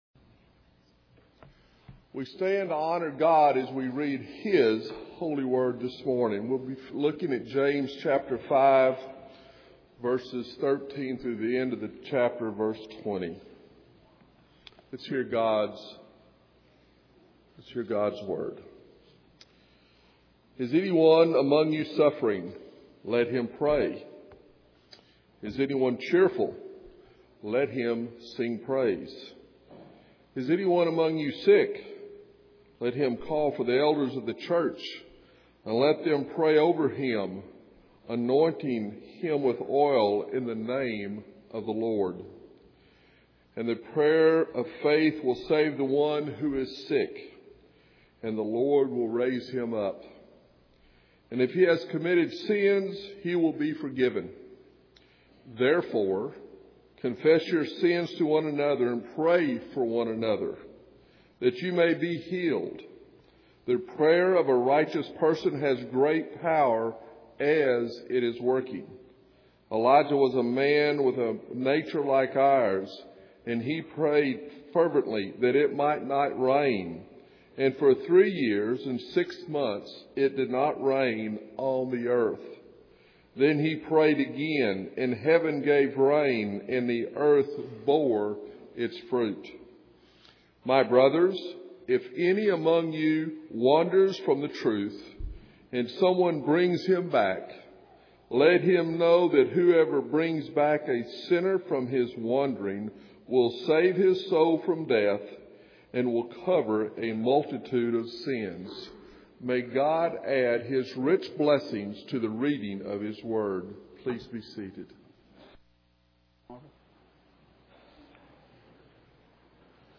Authentic Faith Revealed Passage: James 5:13-20 Service Type: Sunday Morning « Enduring with Patience Dependence Upon God